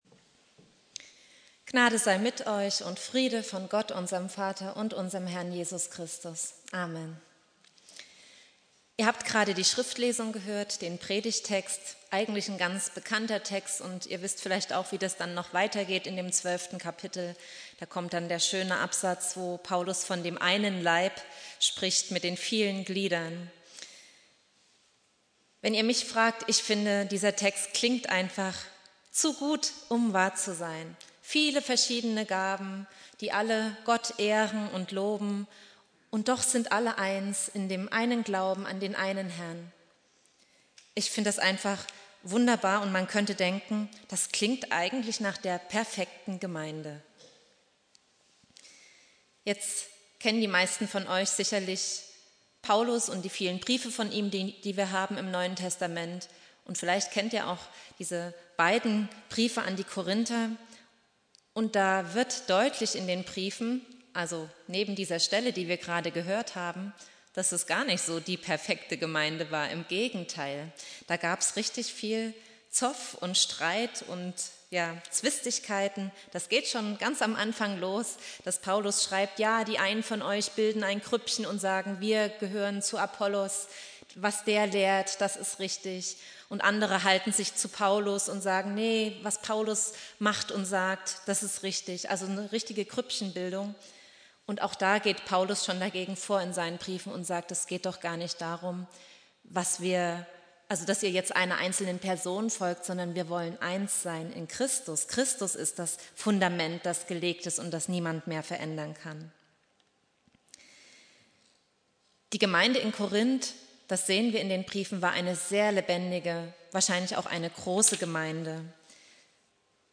Predigt
Pfingstmontag Prediger